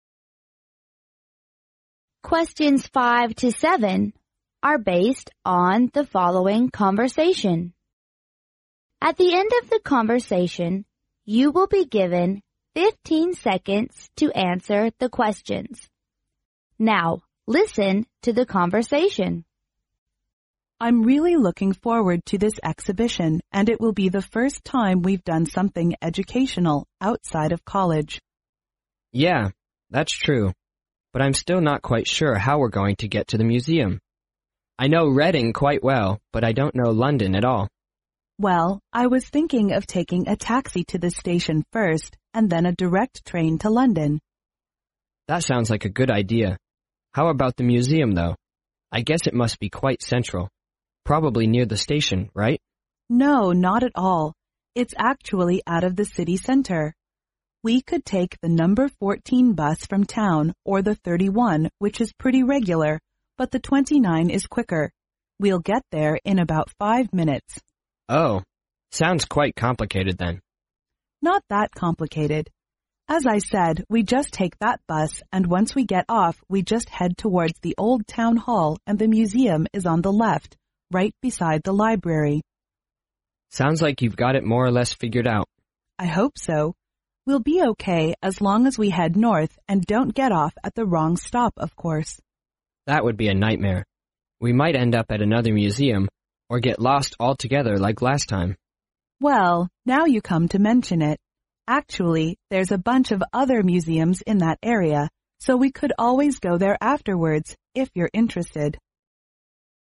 5. According to the woman, which bus will they take in order to save time?